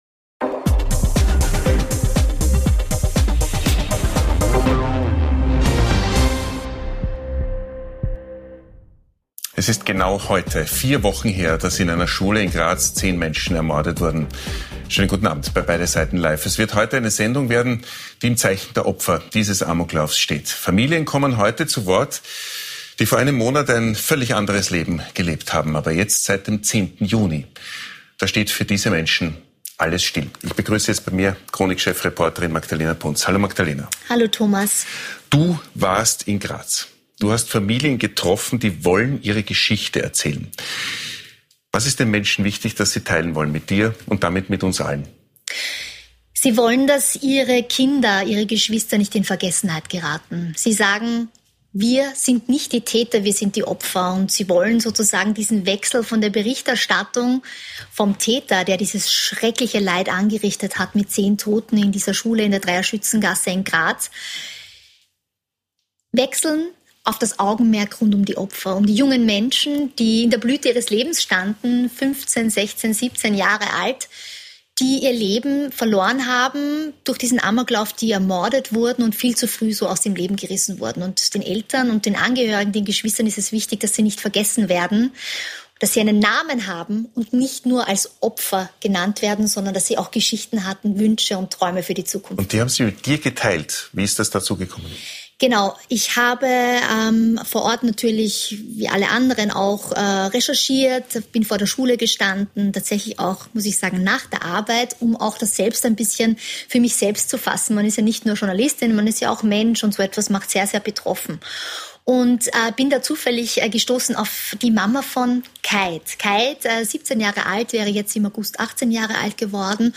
Vier Familien melden sich nach dem Attentat in Graz zu Wort und möchten über die Opfer sprechen. Danach gibt es eine Diskussion darüber, was unsere Schulen brauchen um solche Taten zukünftig zu verhindern.